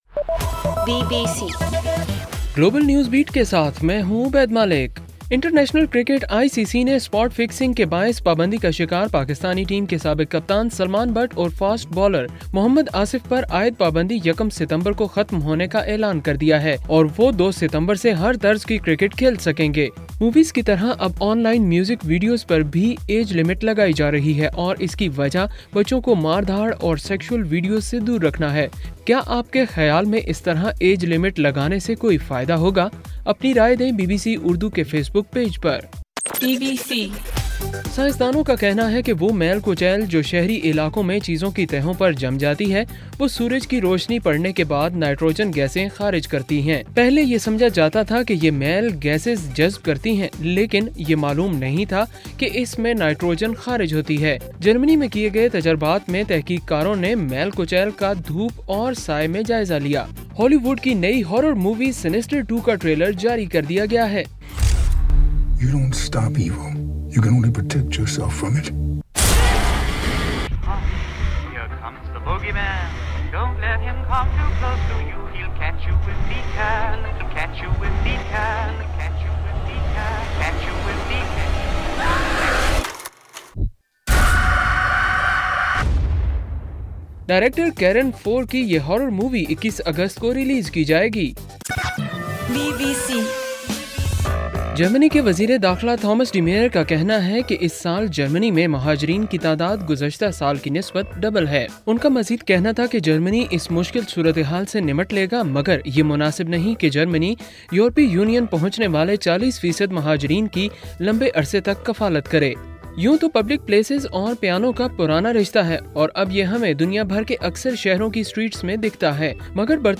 اگست 20: صبح 1 بجے کا گلوبل نیوز بیٹ بُلیٹن